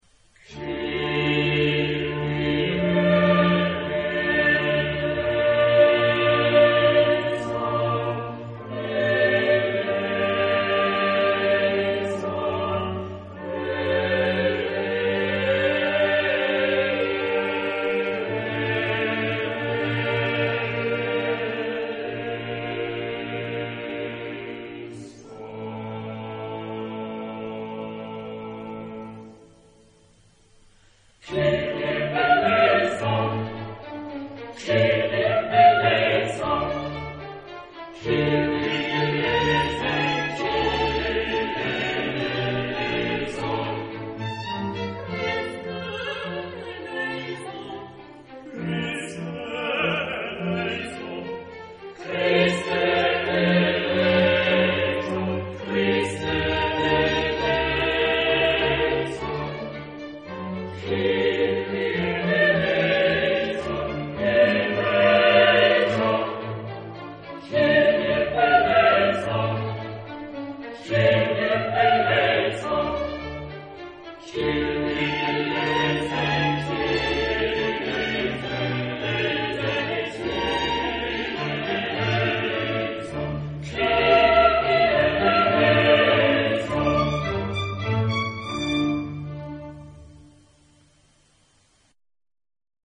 Genre-Style-Forme : Messe ; Sacré ; Classique
Type de choeur : SATB  (4 voix mixtes )